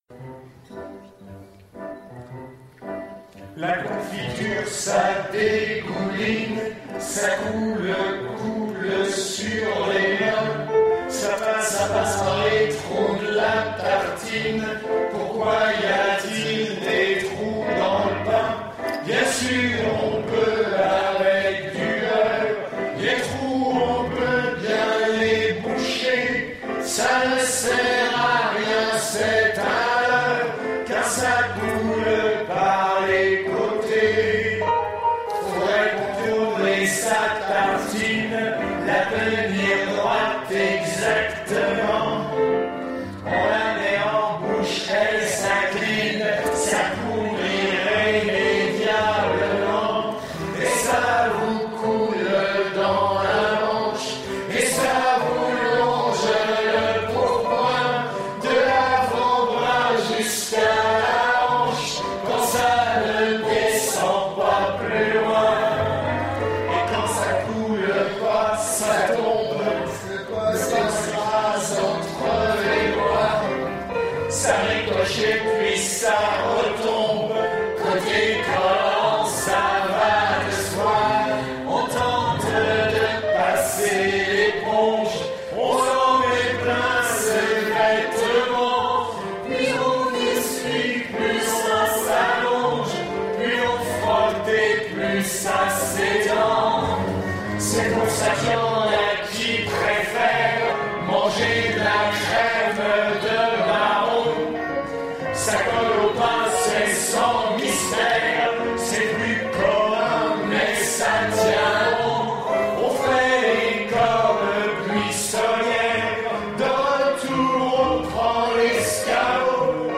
Festival Lacoste 2014 LIVE